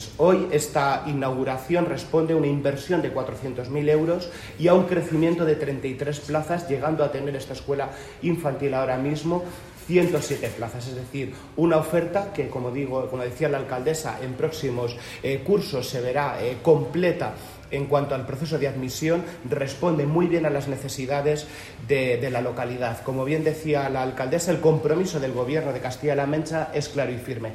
Lo ha avanzado el consejero de Educación, Cultura y Deportes, Amador Pastor, en la inauguración, a cargo del presidente de Castilla-La Mancha, de la ampliación de la Escuela Infantil ‘Arco Iris’ de Socuéllamos (Ciudad Real).